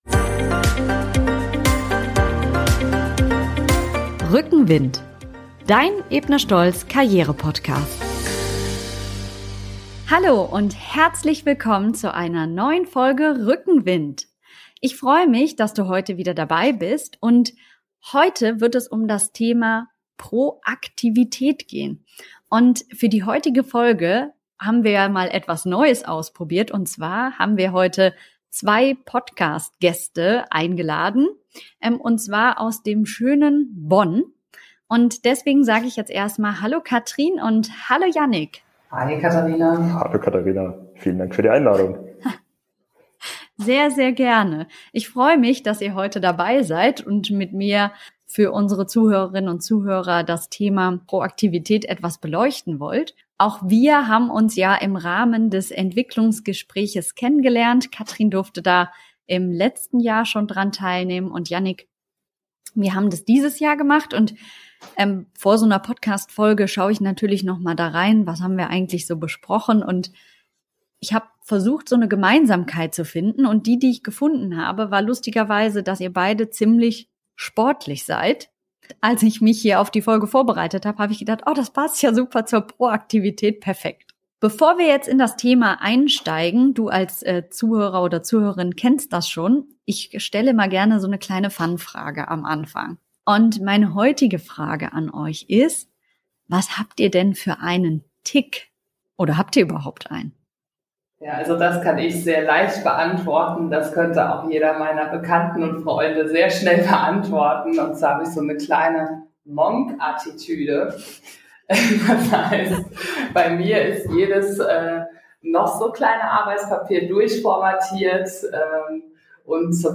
In einer lebendigen Diskussion